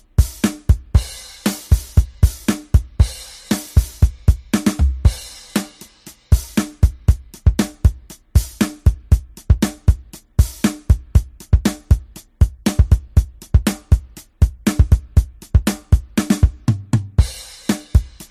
Karaoke drum pattern